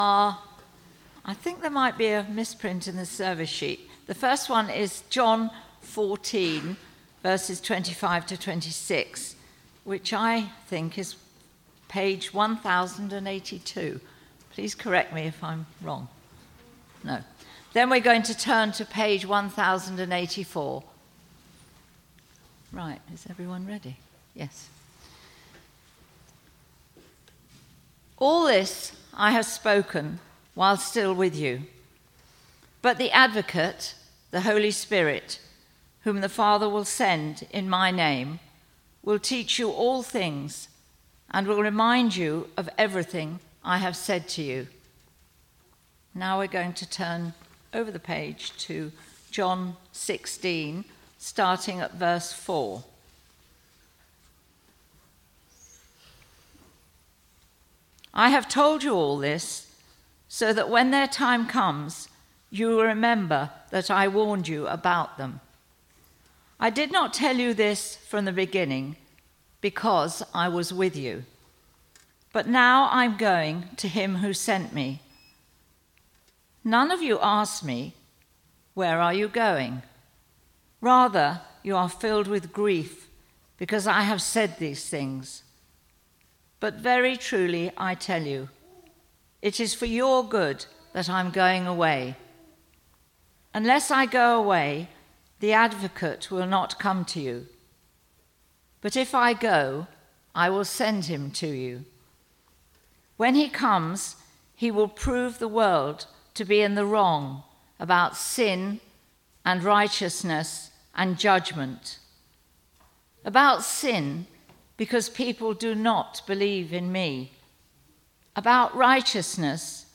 1 1/12 16:00 Church at The Green Service